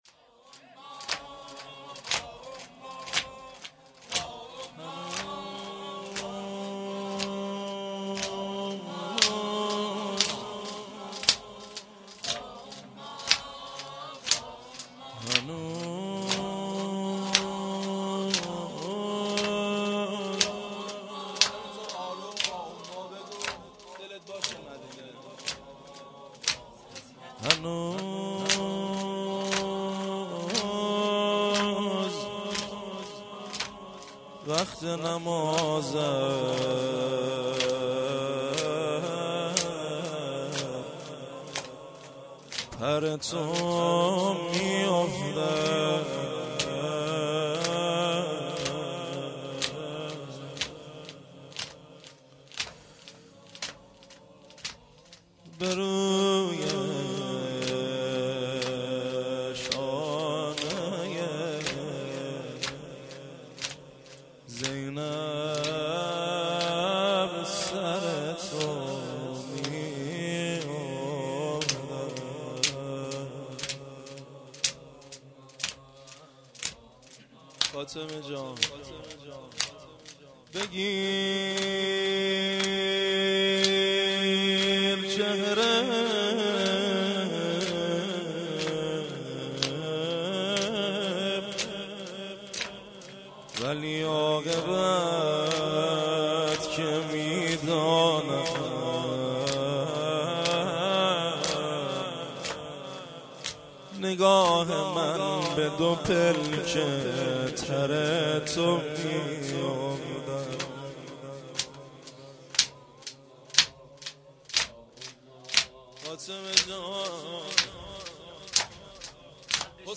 توسل هفتگی-روضه حضرت زهرا(س)-27 بهمن 1396